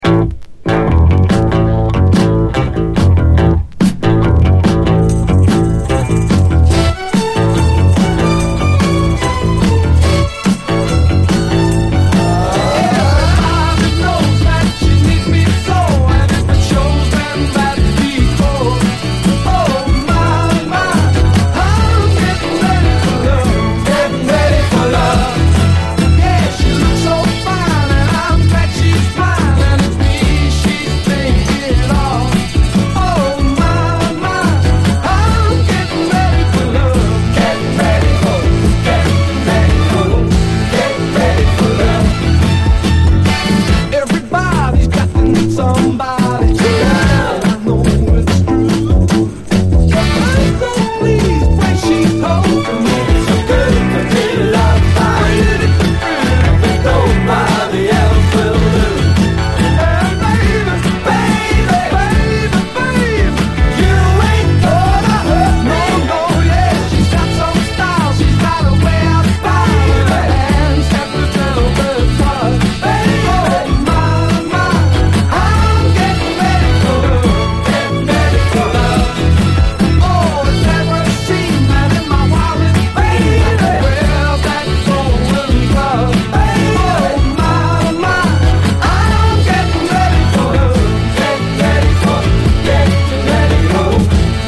SOUL, 60's SOUL, 7INCH
若さが弾けるグレイト・ノーザン・ダンサー！